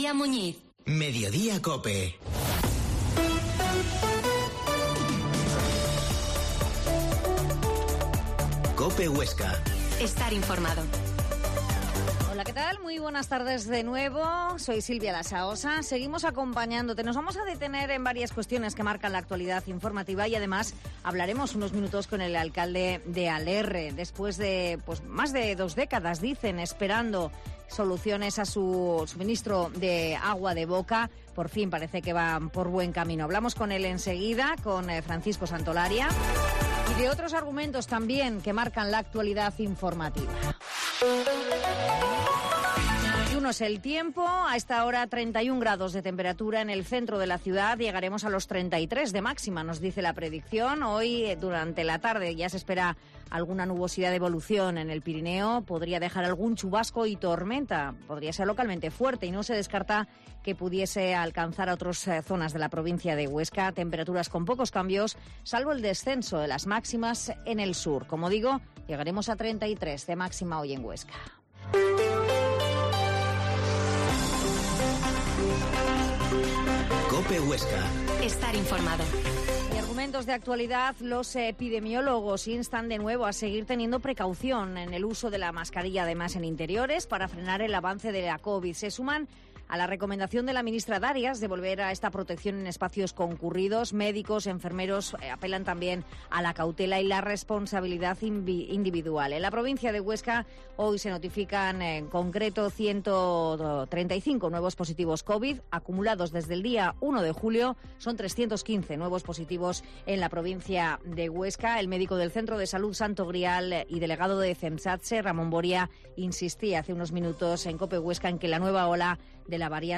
Mediodía en COPE Huesca 13.50 h Entrevista a Francisco Santolaria, Alcalde de Alerre